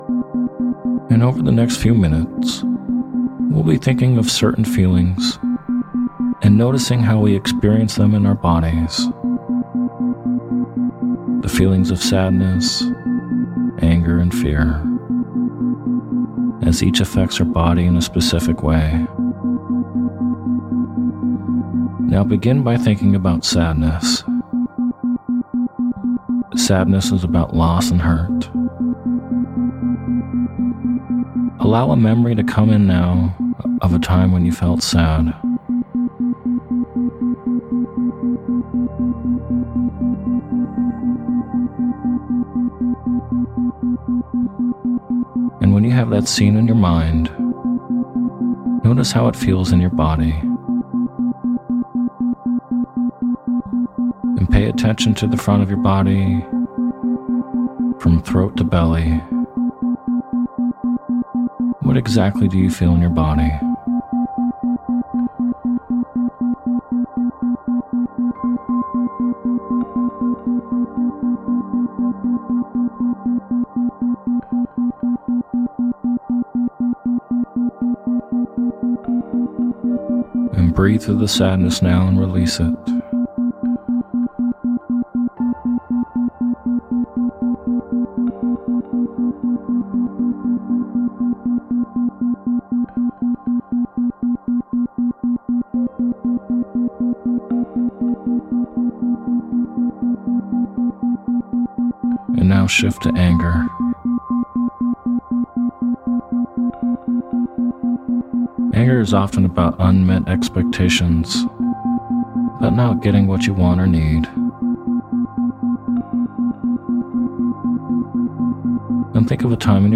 Sleep Hypnosis For Locating Feelings In The Body With Isochronic Tones
In this hypnotic meditation, you’ll be guided to locate positive and loving feelings in the body.